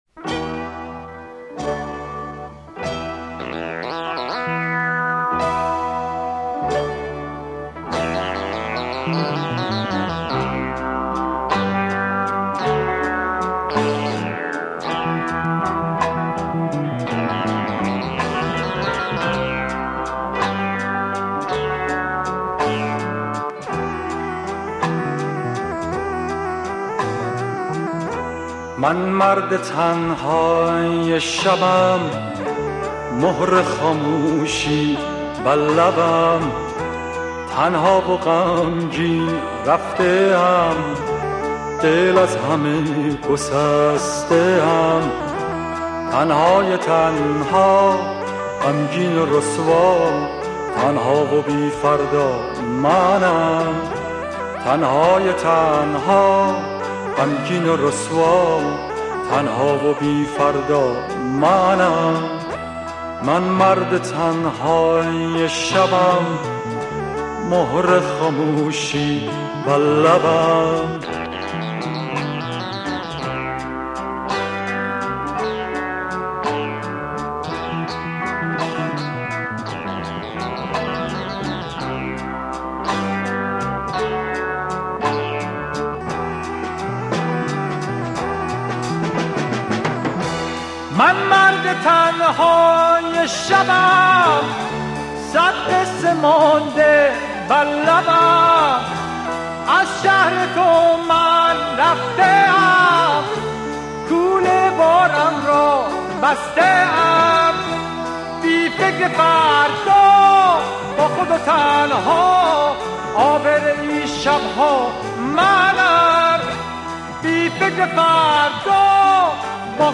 موزیک پاپ ایرانی موزیک ایرانی قدیمی